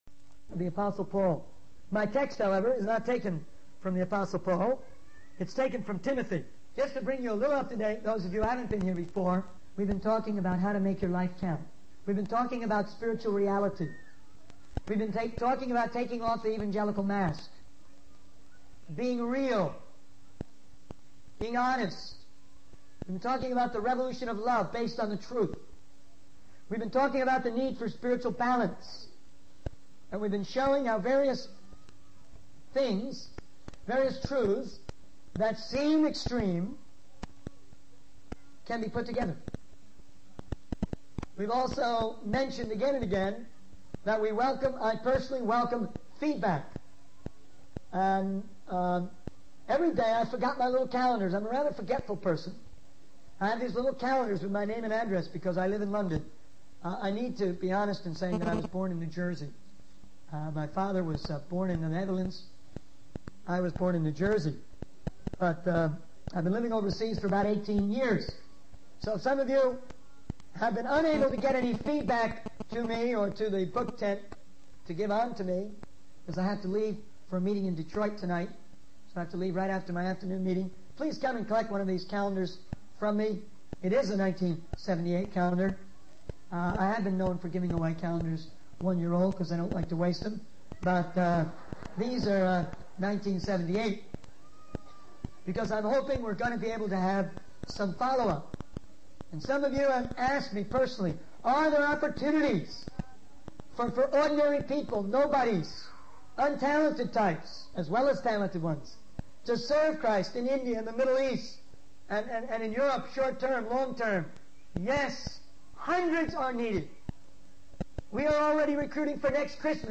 In this sermon, A.W. Tozer emphasizes the importance of spreading the gospel and evangelizing to the regions beyond. He quotes Paul in 1 Corinthians 4:9, highlighting the sacrifices and challenges faced by the apostles in their mission.